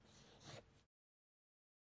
包装纸 " Paper04drawing3
描述：用大号Sharpie（TM）永久性记号笔在大张的包装纸上画画。 这充满了某种计划性，因为它听起来像是有人简单地写了几个字。
标签： 着色 绘图 线 标记 报纸 书写
声道立体声